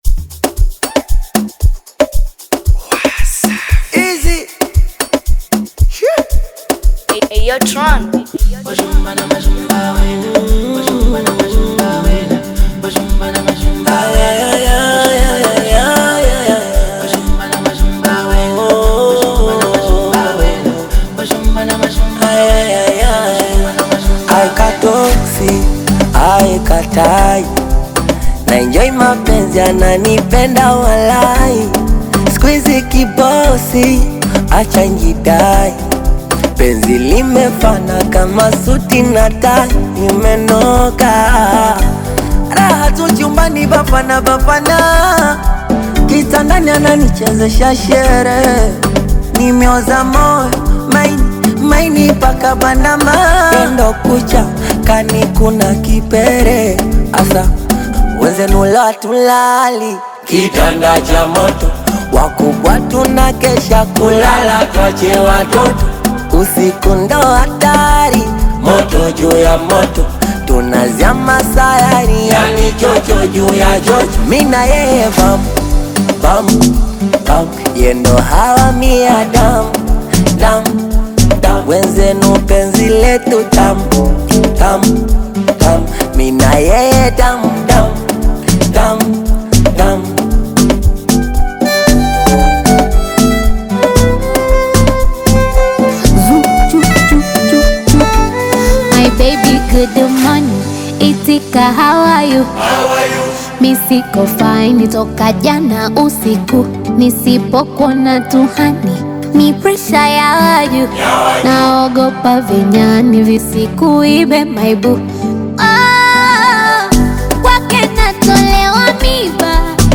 Singeli, Bongo Fleva, Amapiano, Afro Pop and Zouk